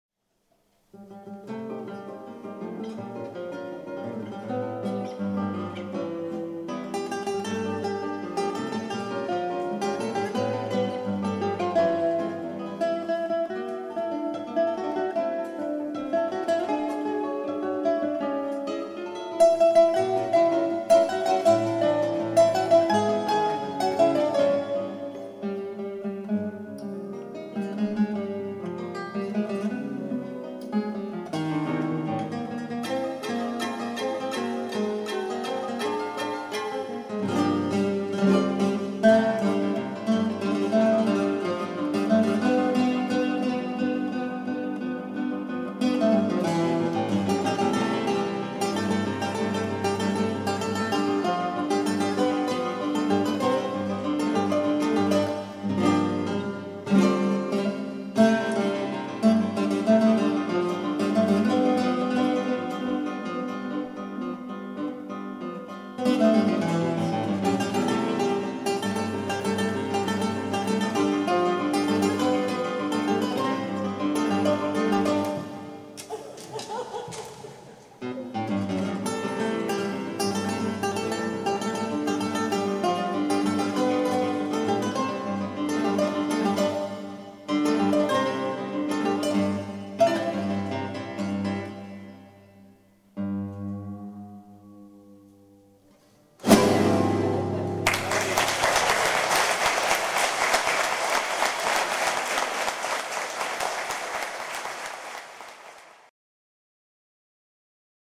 for 3 guitars | per 3 chitarre